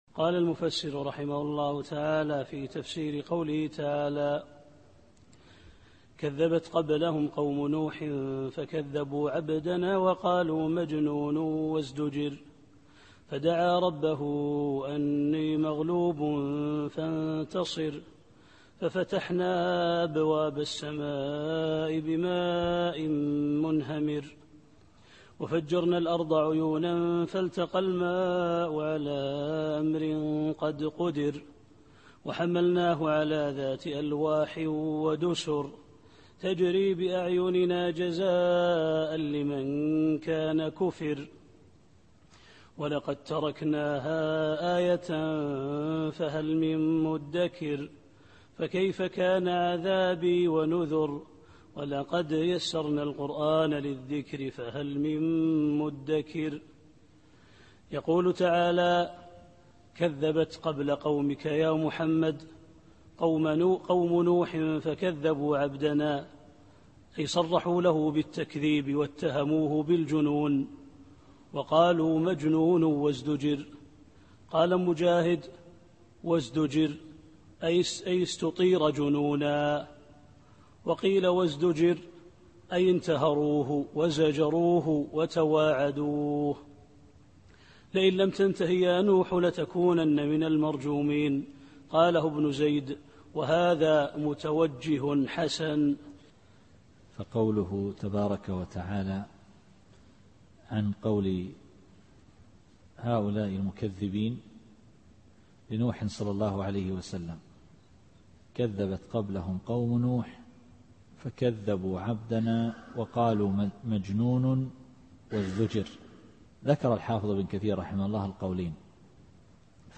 التفسير الصوتي [القمر / 9]